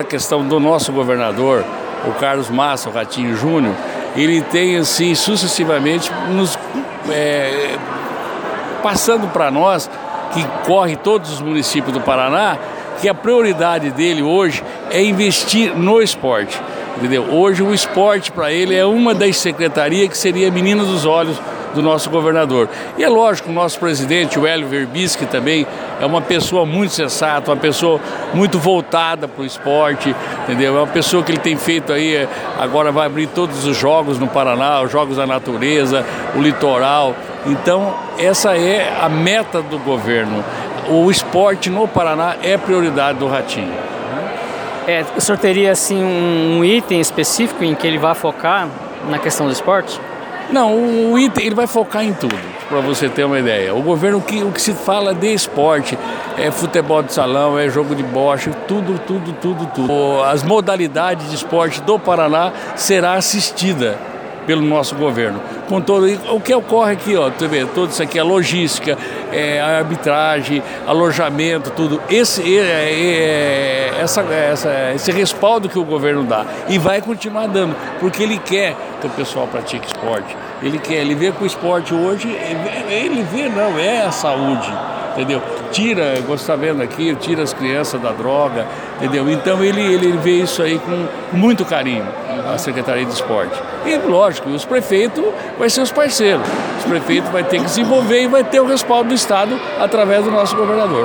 O secretário Donizete, representando o governador Ratinho Júnior, teceu elogios a organização dos Jogos da Juventude em Andirá e disse que o governador tem grande preocupação com o esporte, pois trata-se da menina dos olhos do governador:
Donizete_Secretario-estadual-de-esportes.mp3